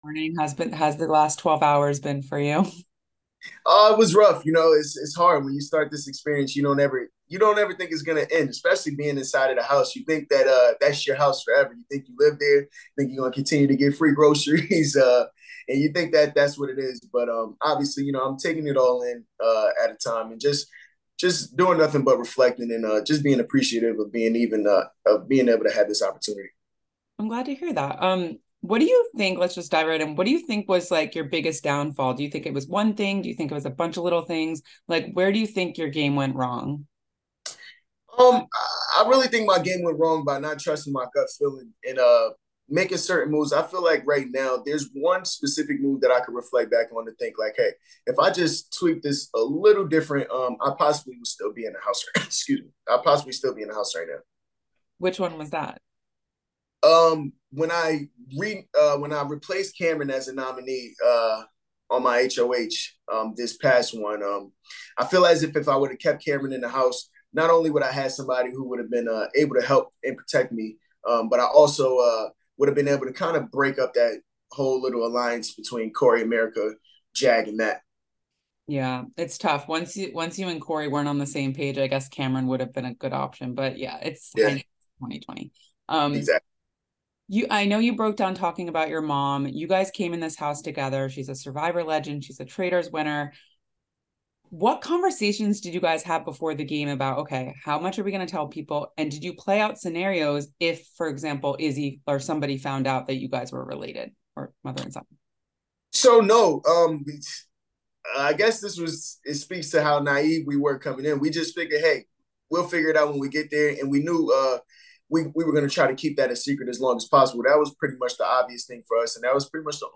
Big Brother 25 Exit Interview